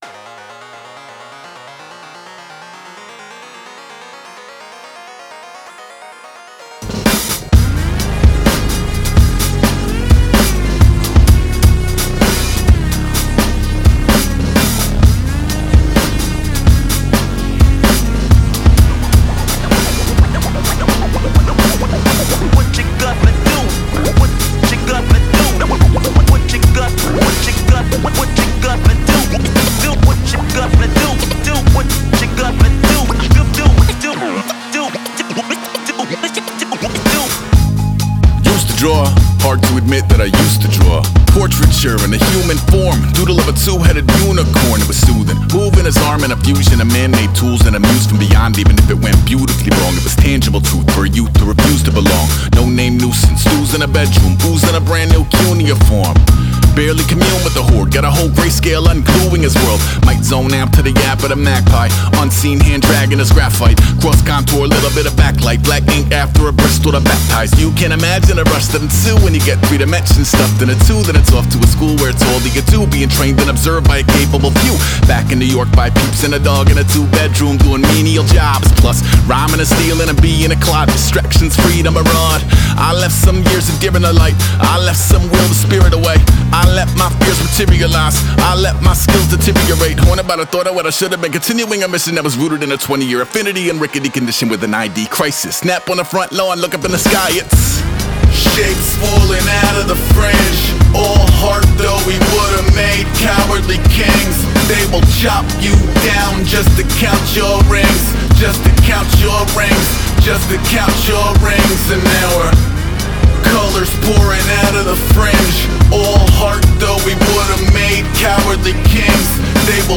Жанр: Rap.